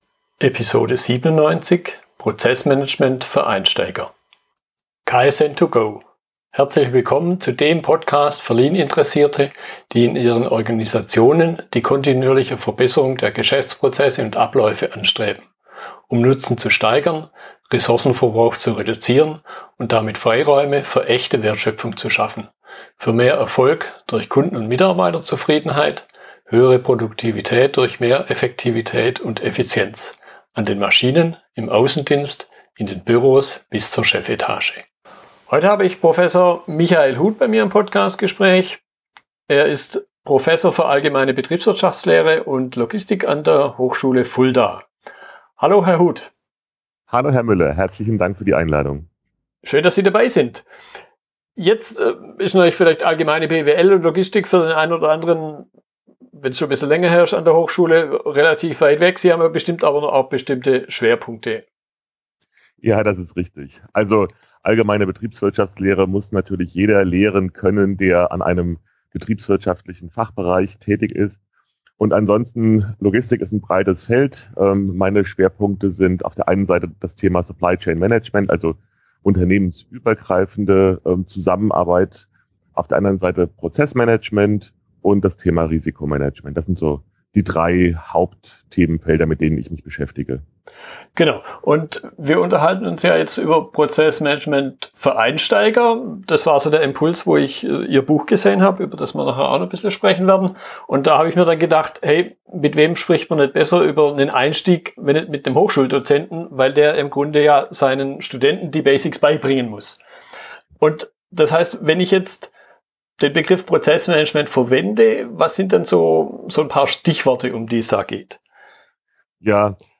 Unterhaltung